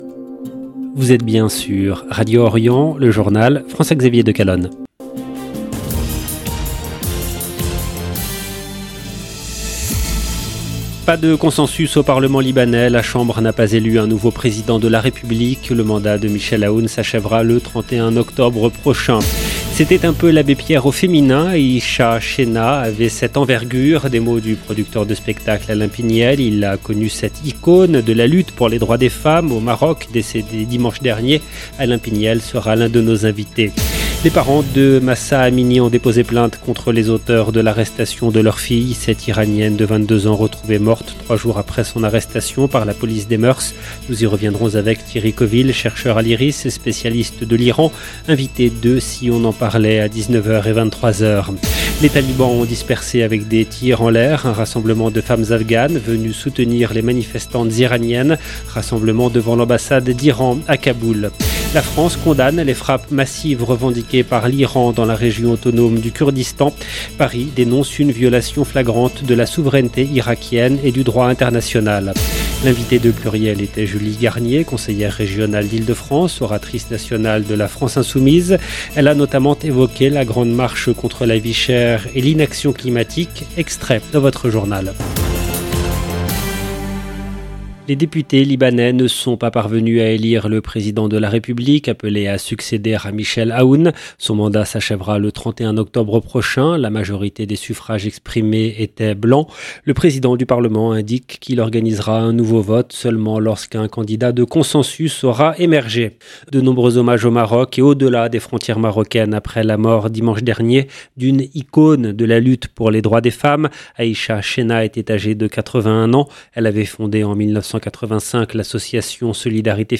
L’invitée de PLURIEL était Julie Garnier, conseillère régionale d’Ile-de-France, oratrice nationale de la France Insoumise.
Extrait dans votre journal. 0:00 16 min 39 sec